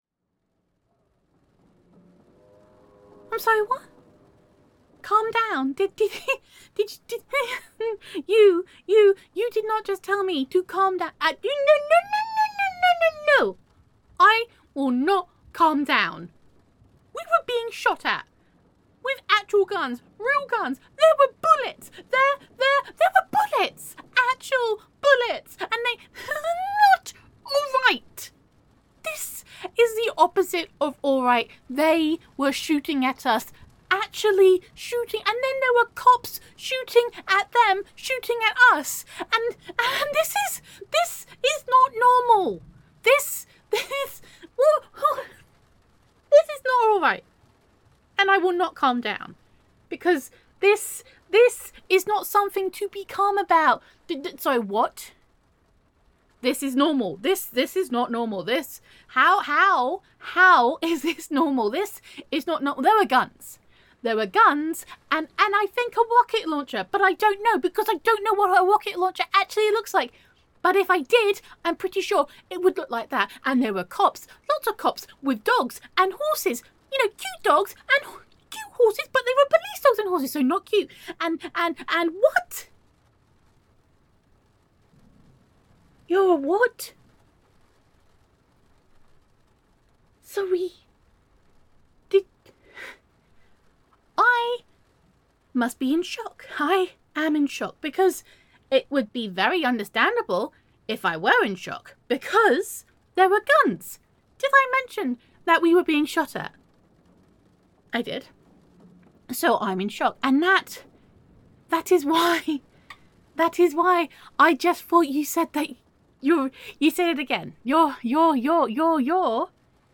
PATREON EXCLUSIVE AUDIO – [F4A] Lobster [Mobster Listener][Librarian Girlfriend[You Never Told Me That][Misunderstandings][They Were Shooting At Me][Do the Police Think I Am Cool?][Girlfriend Roleplay][Gender Neutral][Your Girlfriend Thought You Were a Lobster Not a Mobster]